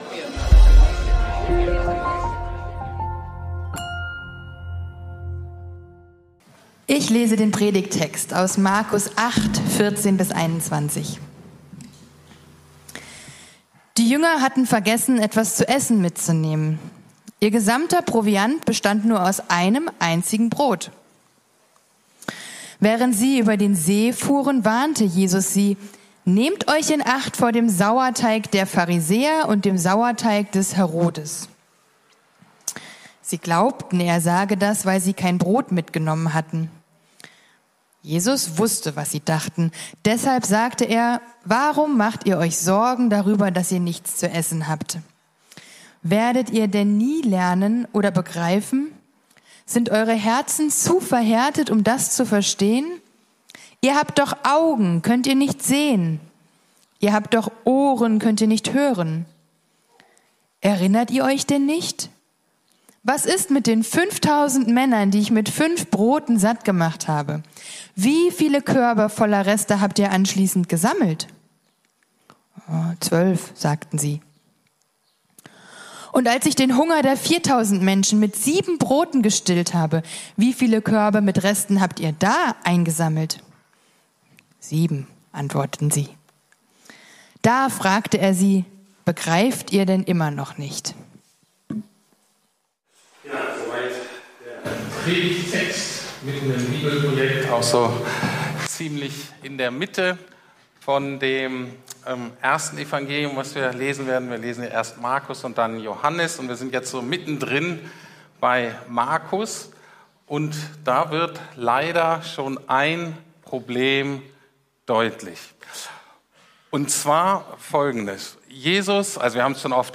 Das Problem der Nachfolge ~ Predigten der LUKAS GEMEINDE Podcast